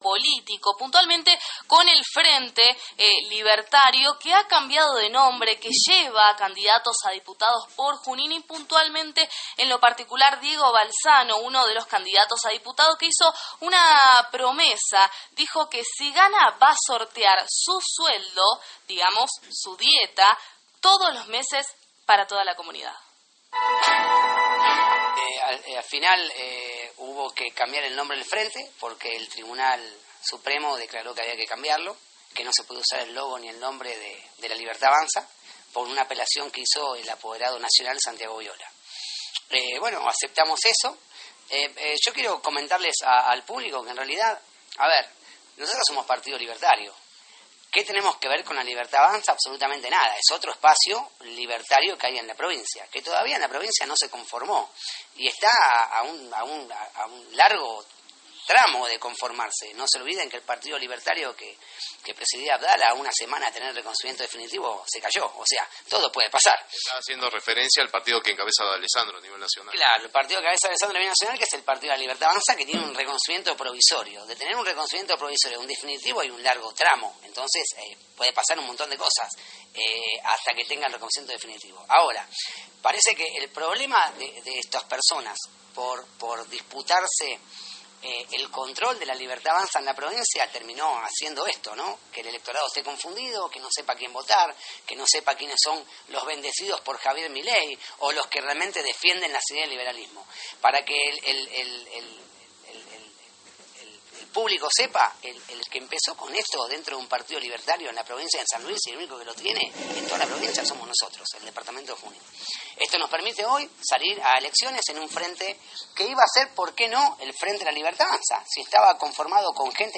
ayer ante las cámaras de Merlo TV.